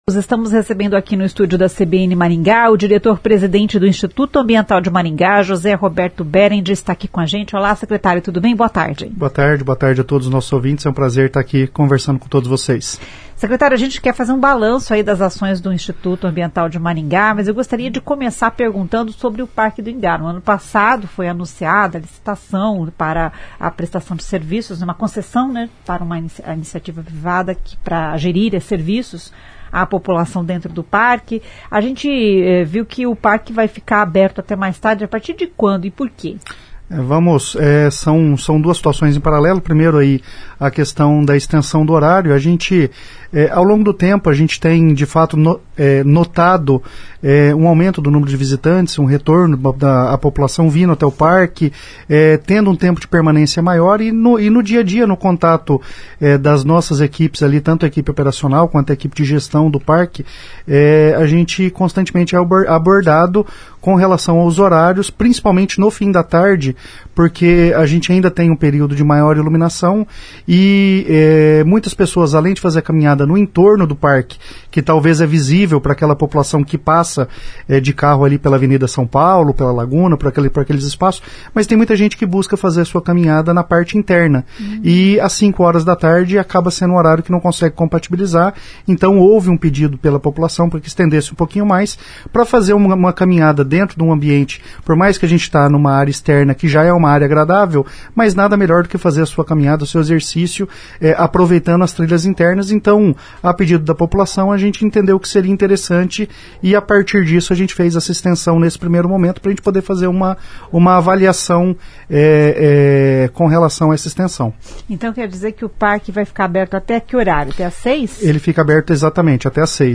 O diretor-presidente do IAM, José Roberto Behrend, explica que a pedidos o horário de funcionamento do Parque do Ingá.